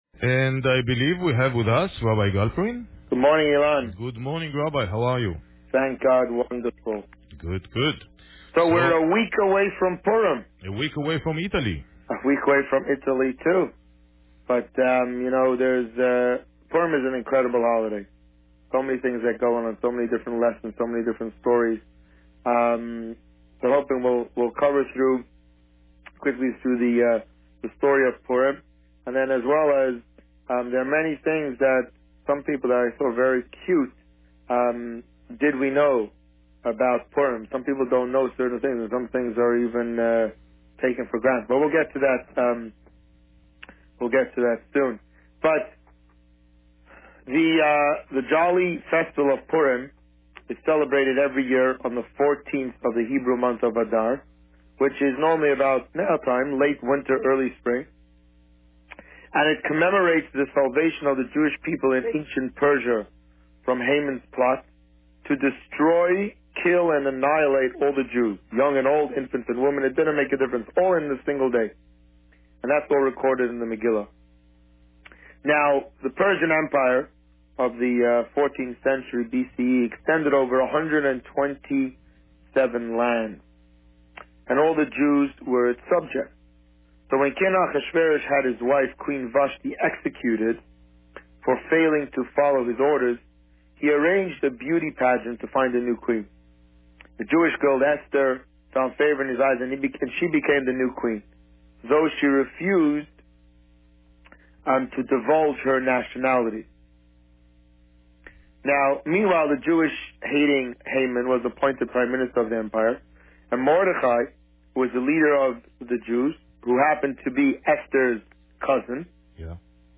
This week, the Rabbi spoke about the story of Purim and the upcoming Purim party. Listen to the interview here.